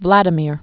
(vlădə-mîr, vlə-dyēmĭr)